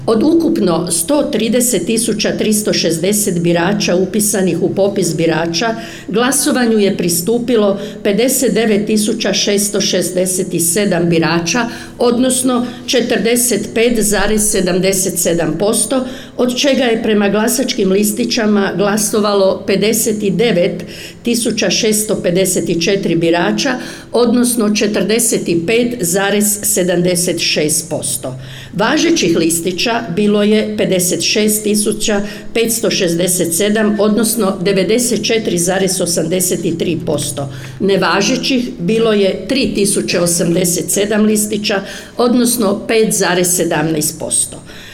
Izvješće Mandatnog povjerenstva podnijela je predsjednica povjerenstva Ivanka Roksandić